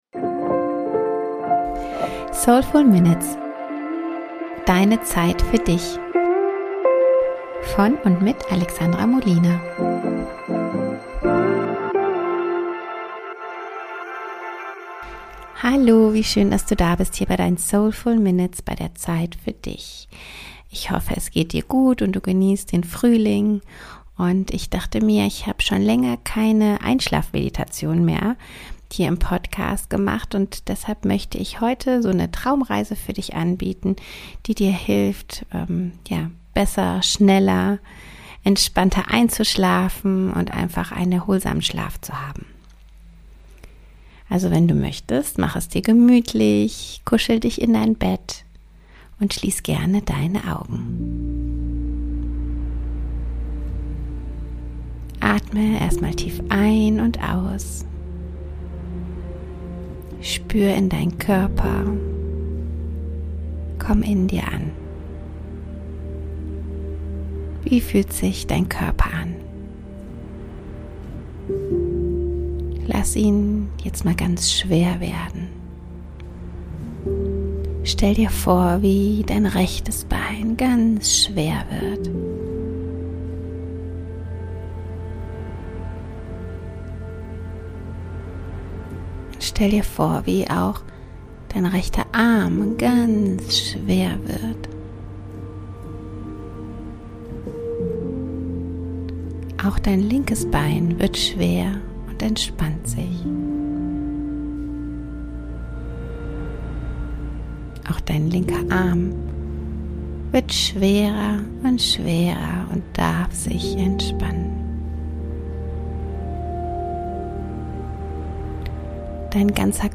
Einschlafmeditation für erholsamen Schlaf